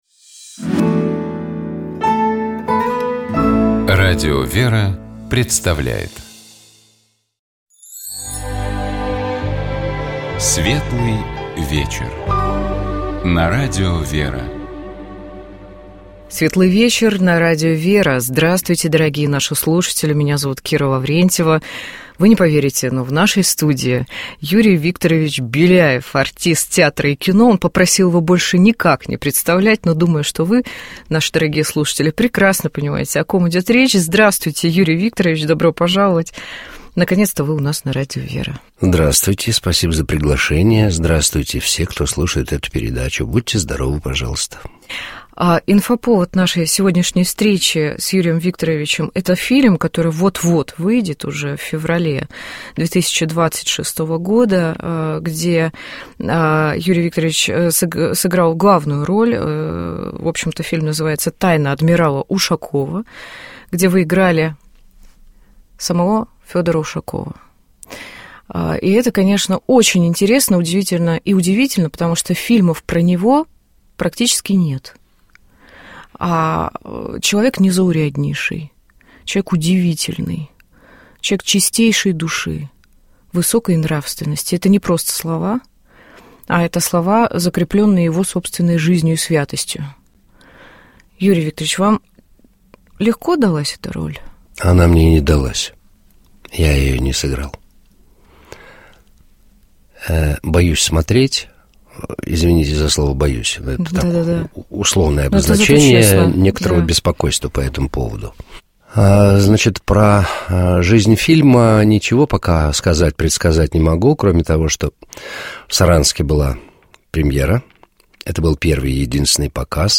Гостем программы «Светлый вечер» был актер театра и кино Юрий Беляев.